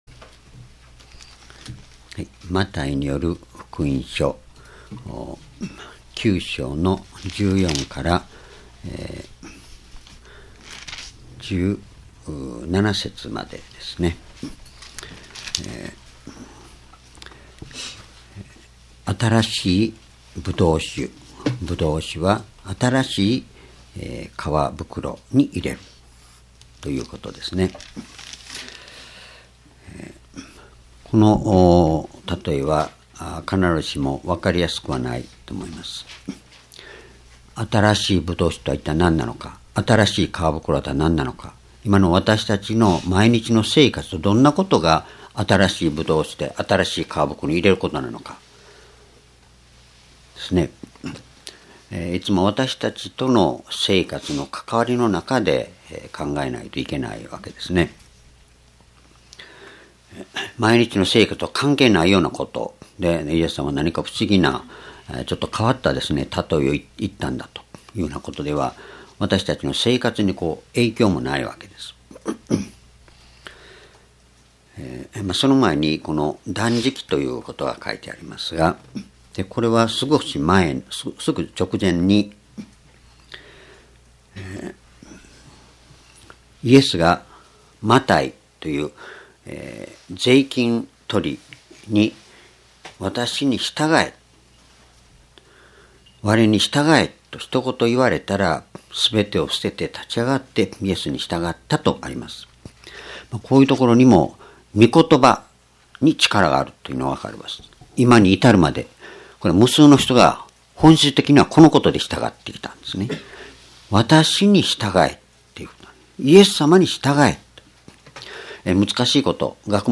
主日礼拝日時 2017年3月26日 聖書講話箇所 マタイ福音書9章14節-17節 「新しいぶどう酒は、新しい革袋に」 ※視聴できない場合は をクリックしてください。